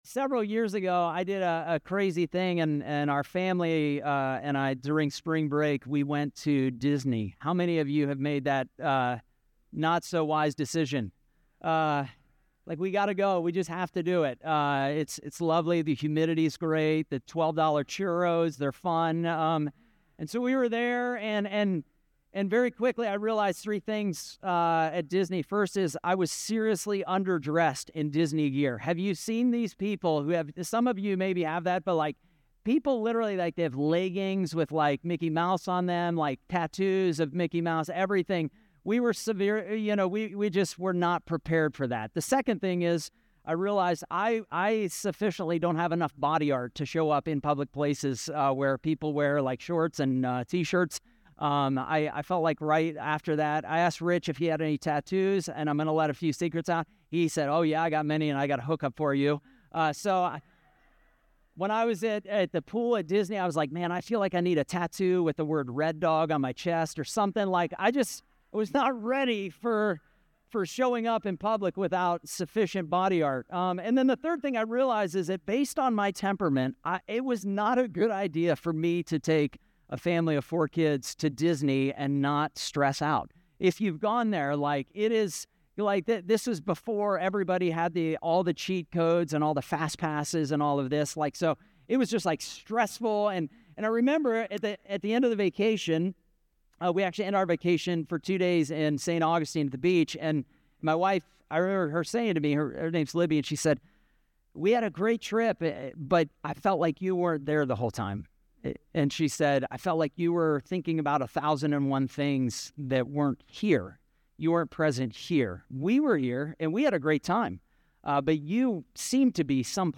Guest Preacher